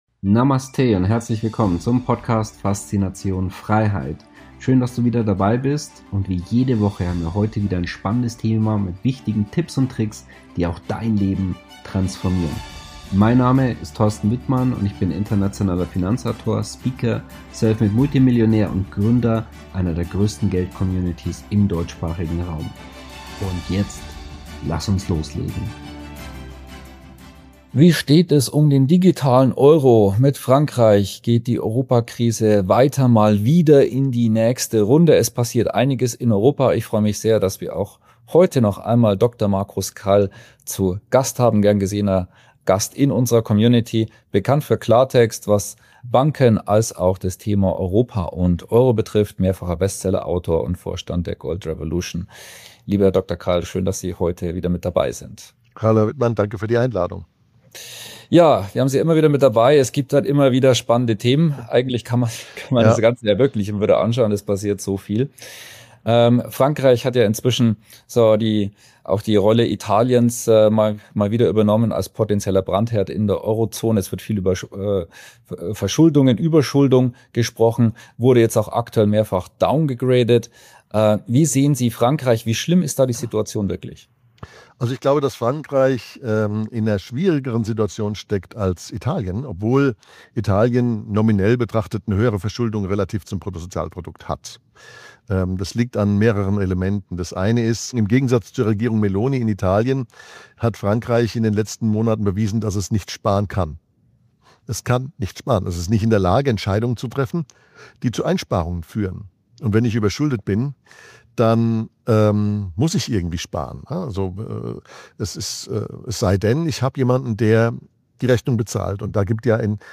Auch heute haben wir noch einmal Dr. Markus Krall in einem brisanten Gespräch zu Gast.